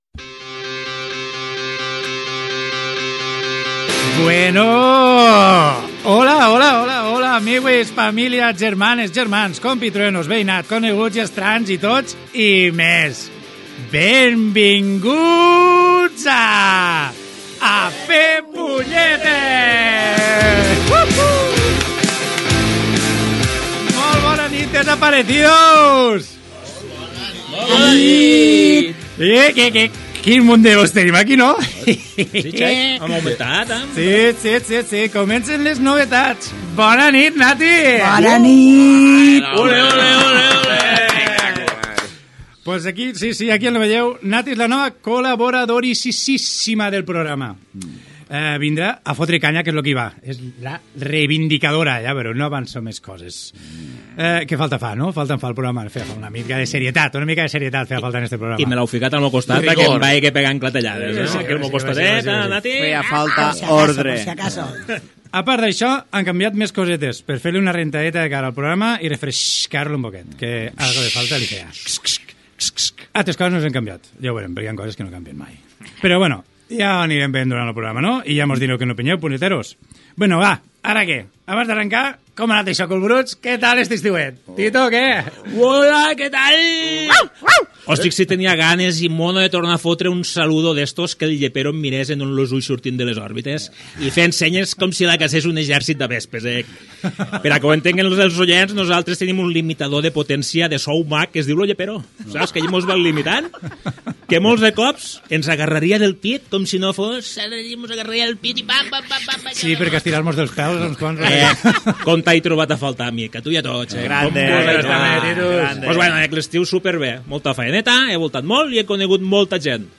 Pública municipal
Entreteniment